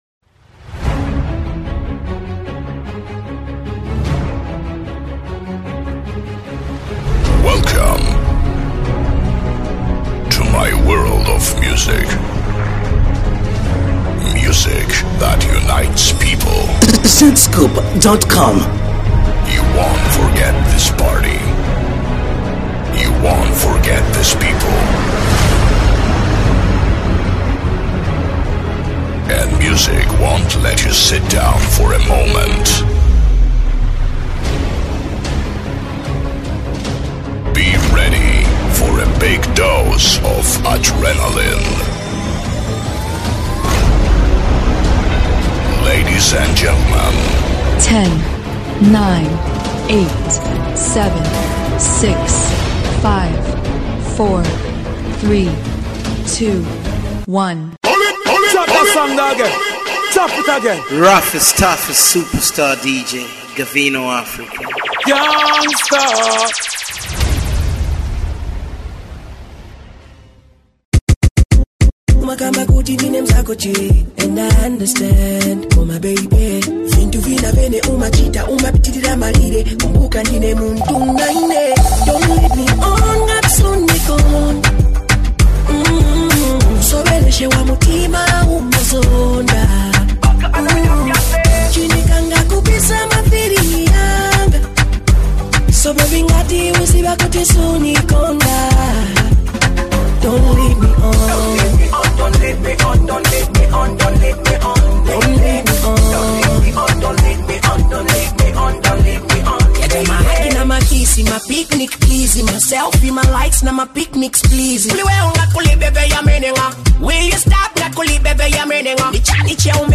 mix maestro